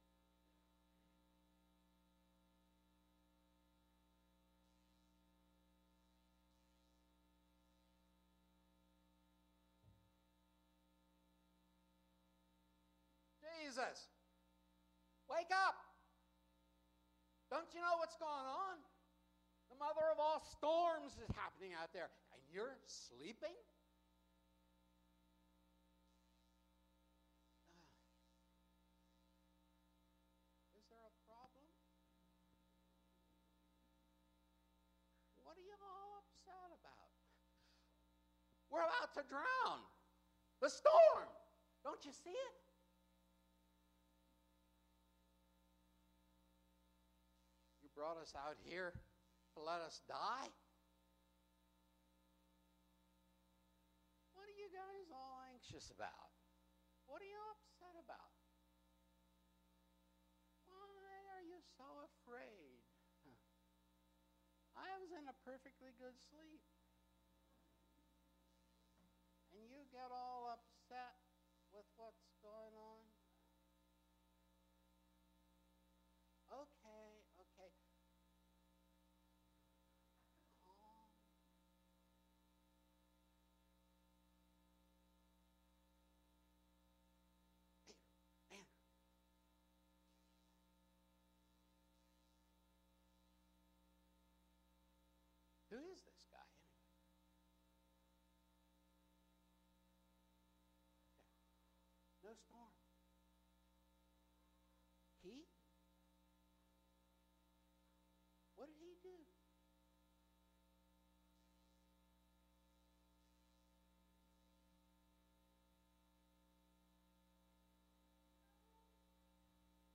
Service 9:30 am Worship